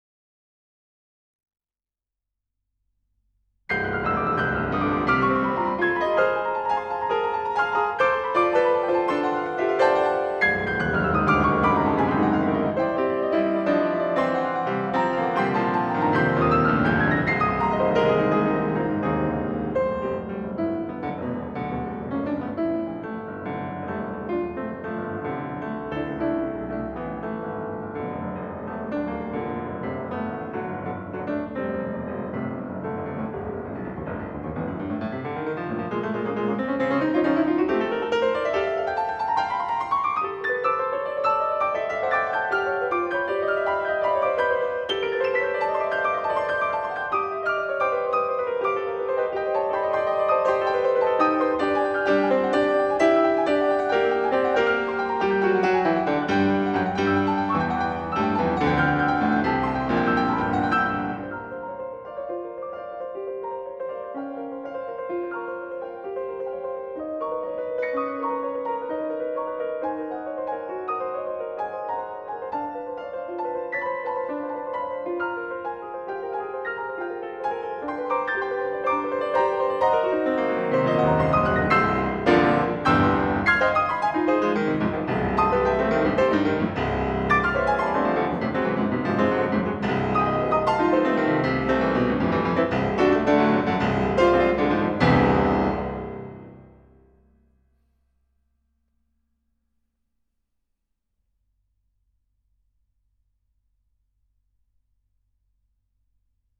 At the same time, it’s a warmup piece in C major.